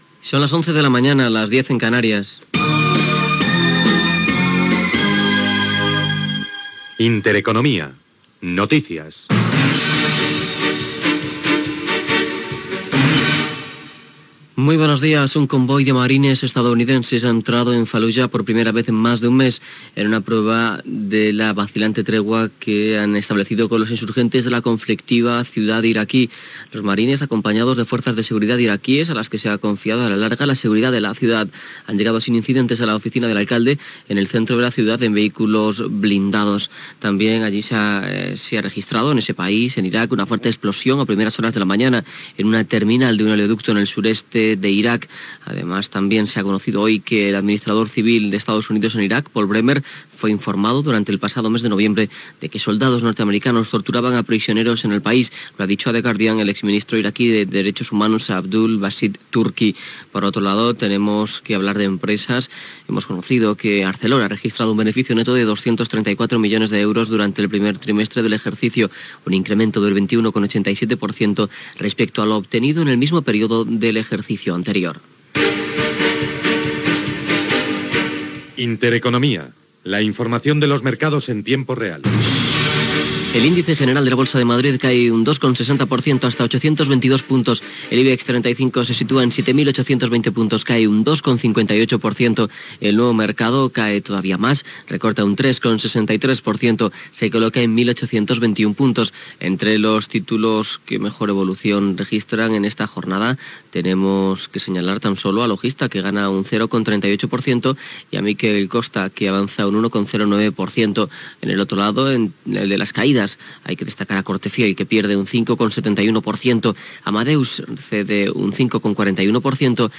Hora, identificació del programa, els marins nord-americans entren a Fal·luja (Iraq), informació econòmica de la borsa i les divises, beneficis de l'empresa Nikkon, publicitat, venda de ràdios digitals a El Corte Inglés. Indicatiu, publicitat, promoció de "El baròmetre", indicatiu
Informatiu
FM